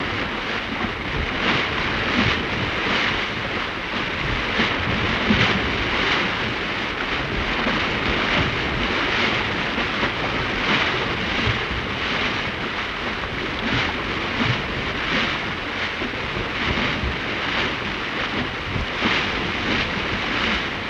Paddle Boat Water Wash